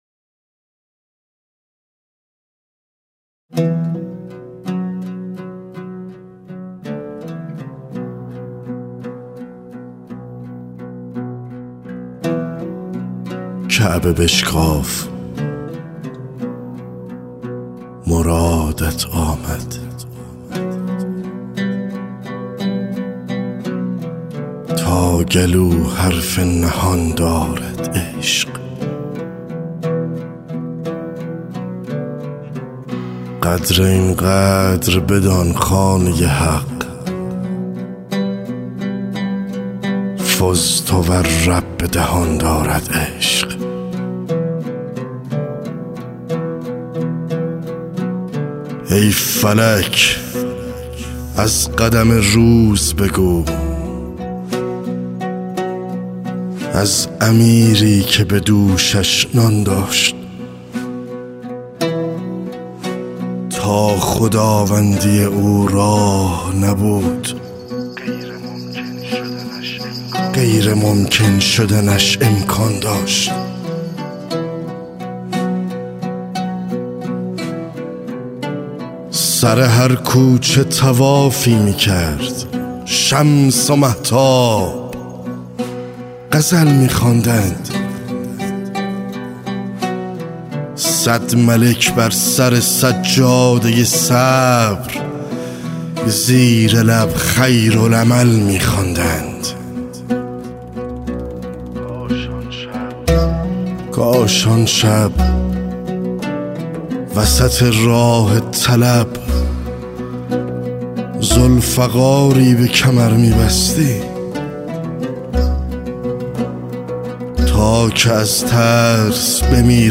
( دکلمه )